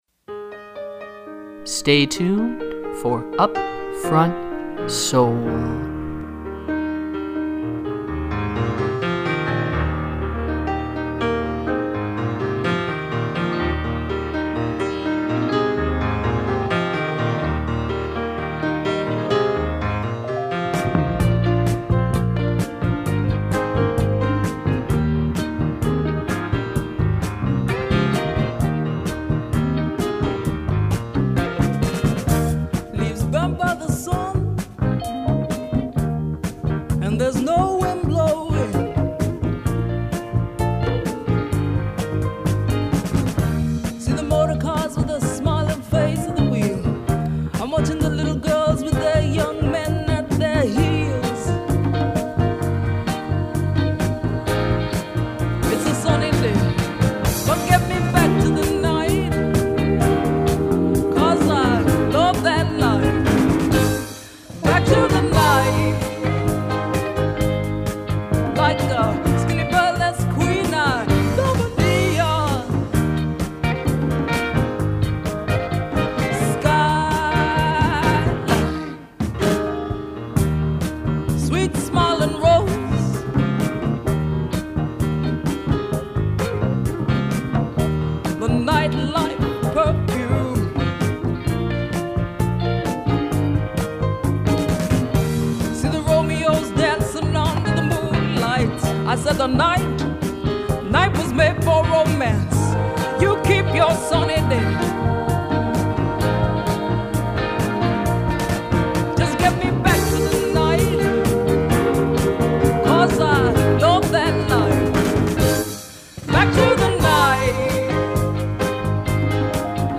Funk, soul, and jazz
120 minutes of soulful sounds to which you may get down.